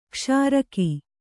♪ kṣāraki